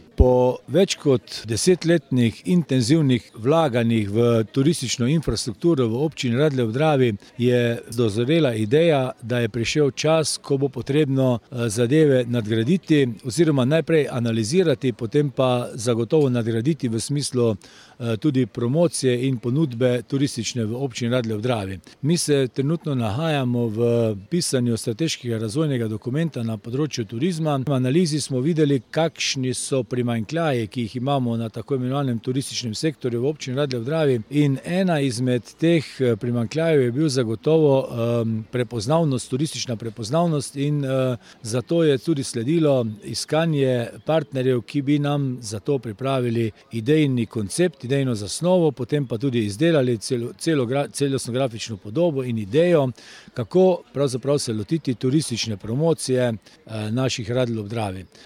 Župan občine Radlje, Alan Bukovnik:
izjava Bukovnik za splet.mp3